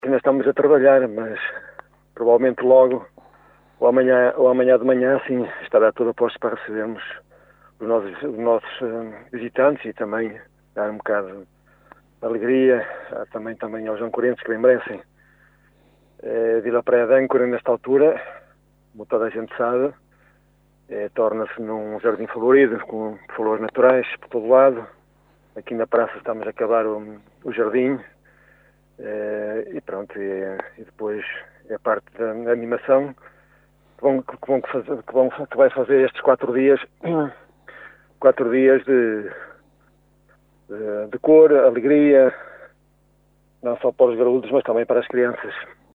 Carlos Castro, presidente da junta de freguesia de Vila Praia de Âncora, está no local a ultimar os preparativos desta grande festa, como revelou em entrevista ao Jornal C – O Caminhense.